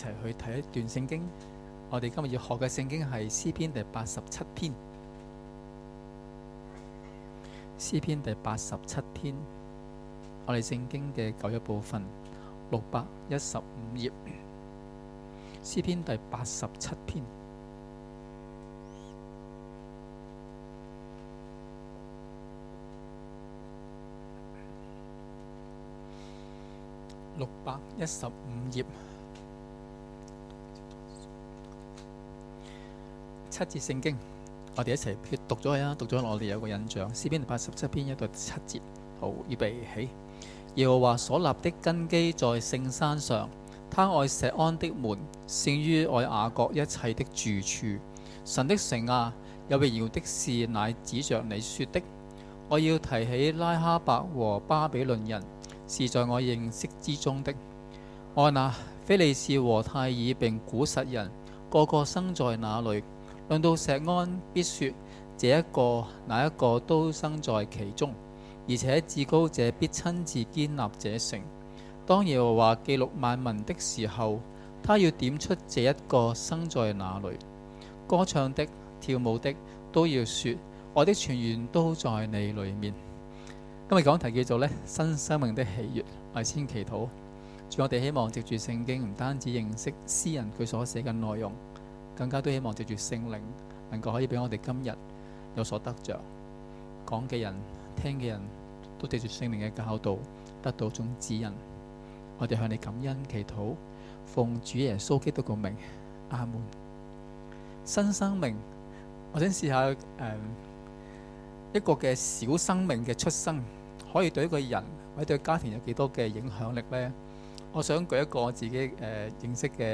崇拜講道